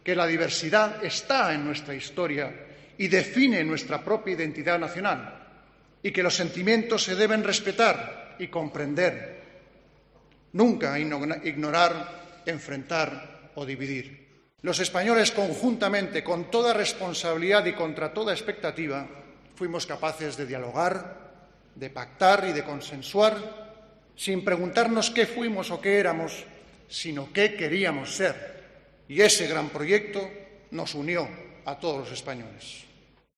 En su intervención ante los diputados y senadores reunidos en una sesión solemne del Congreso para conmemorar el 40 aniversario de las elecciones de 1977, Felipe VI ha subrayado que la diversidad define la propia identidad de España y ha recalcado que "los sentimientos se deben respetar y comprender; nunca ignorar, enfrentar o dividir".